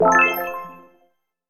pgs/Assets/Audio/Collectibles_Items_Powerup/collect_item_14.wav at master
collect_item_14.wav